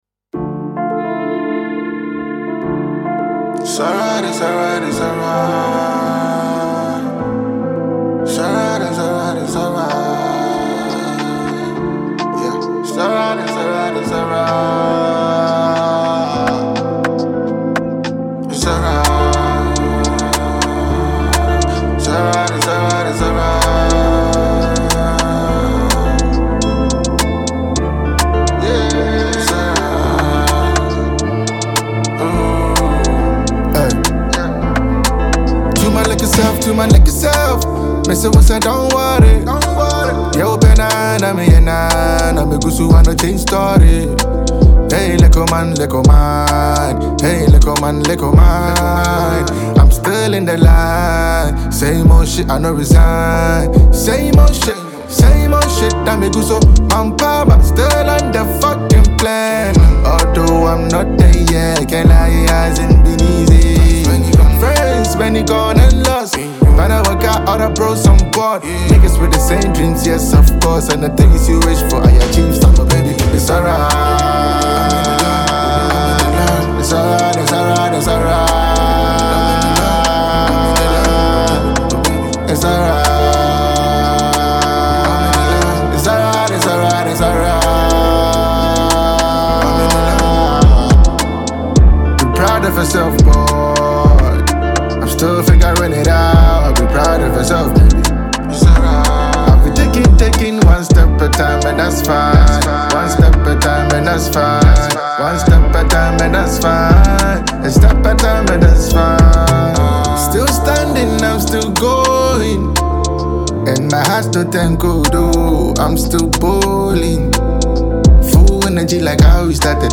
a Ghanaian trapper
Enjoy this magical production from this rapper.